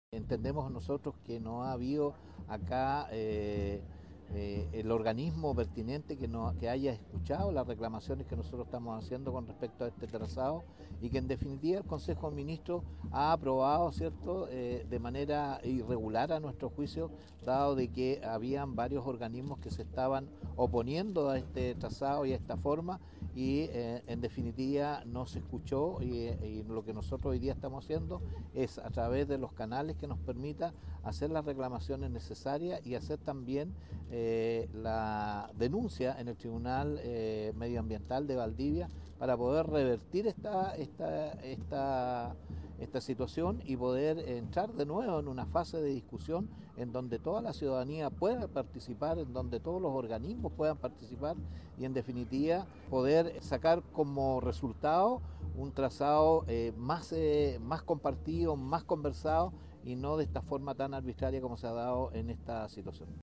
Ricardo Fuentes, alcalde de Hualqui y presidente de la Asociación de Municipalidades de la Región del Biobío, explicó la preocupación que genera el proyecto.